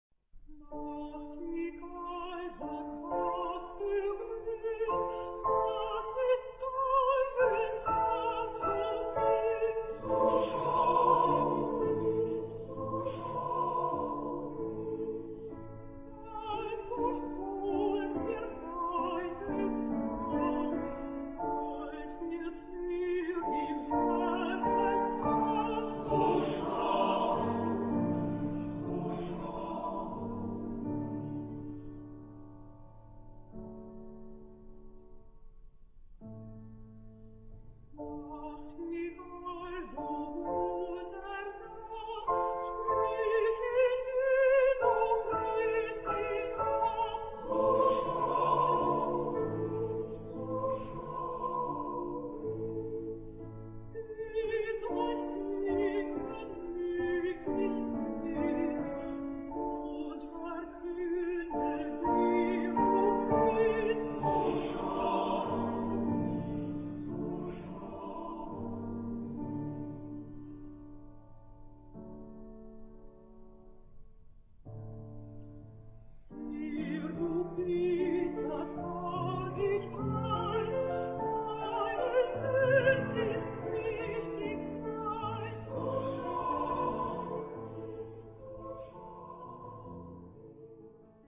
Type de choeur : SATB  (4 voix mixtes )
Solistes : Soprano (1) / Alto (1)  (2 soliste(s))
Instruments : Piano (1)
Tonalité : ré mineur